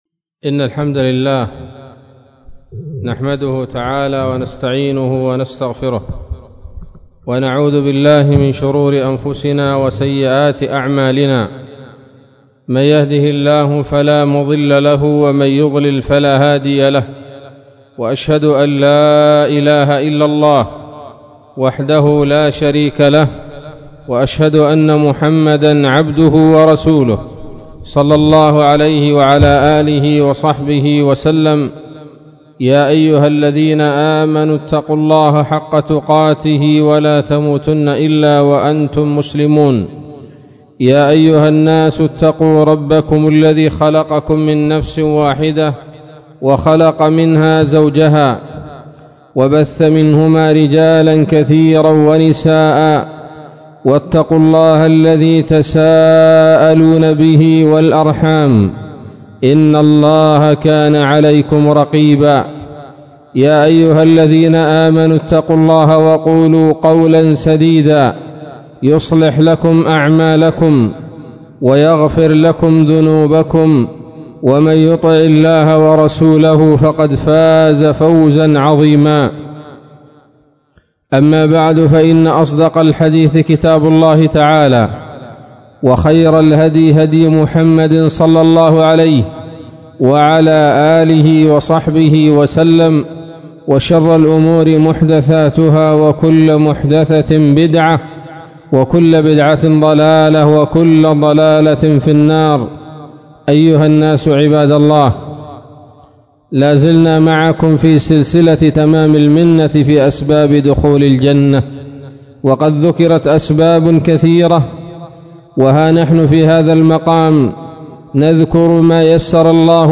خطبة بعنوان : ((تمام المنة في ذكر بعض أسباب دخول الجنة [6])) 08 ربيع الثاني 1438 هـ